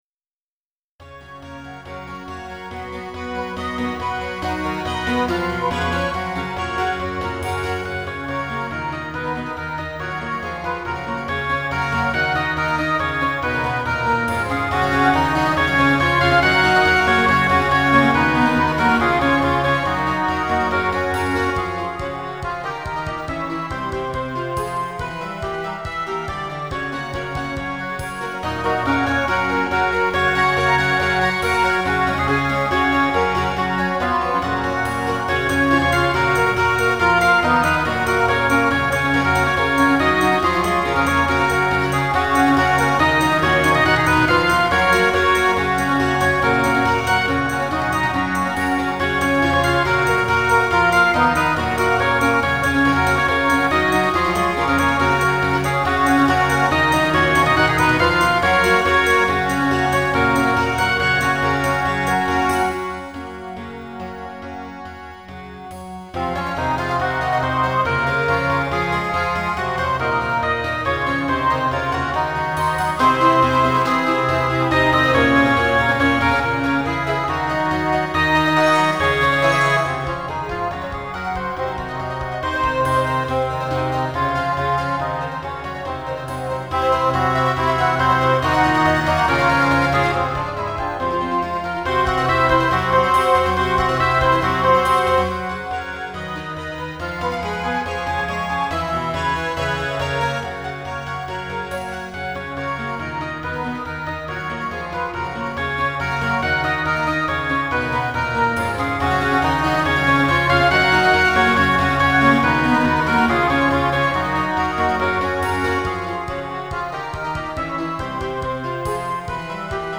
嬰ヘ長調